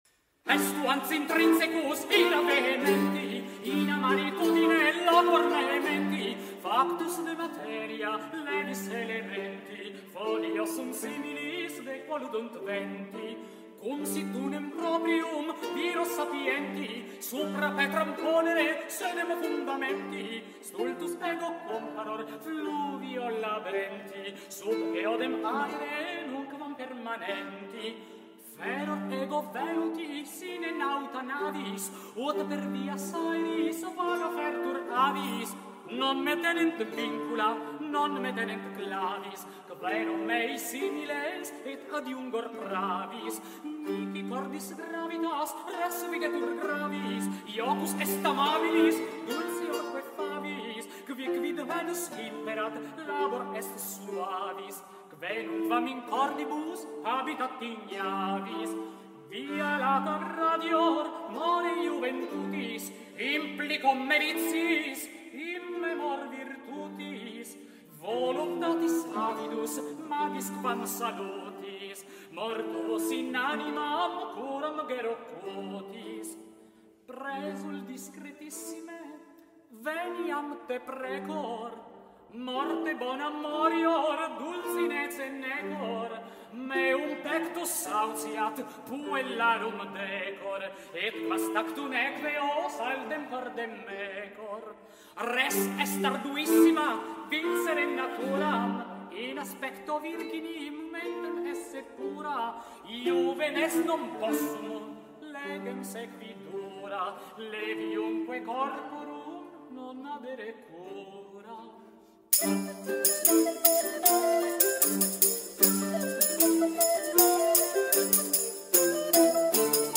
Concert Festival Cusiano, 2007, live audio recording.
voce
flauti diritti, ciaramelle
viella
arpa
liuto